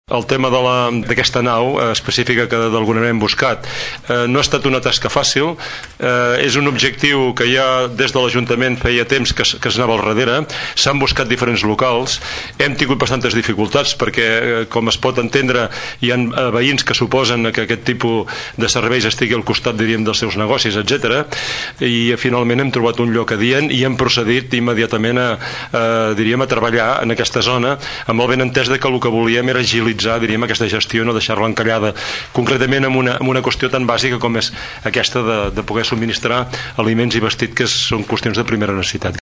Escoltem Àngel Pous.